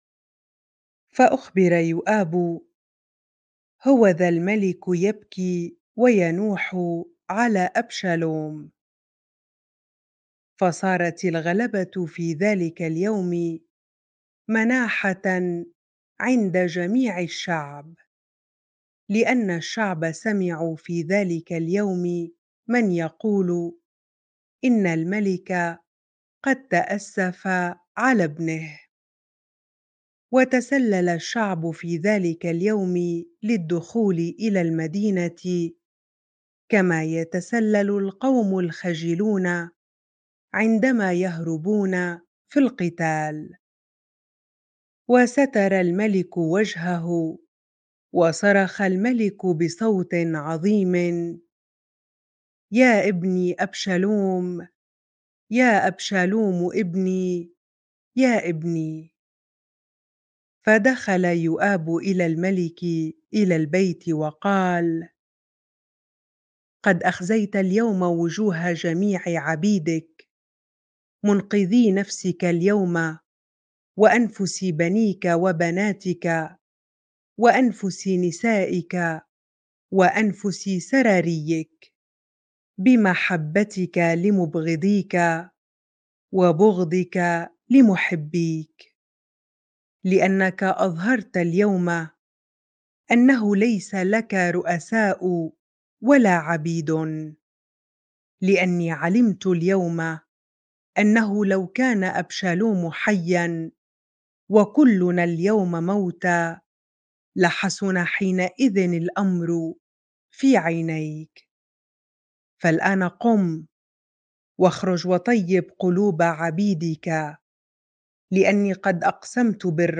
bible-reading-2Samuel 19 ar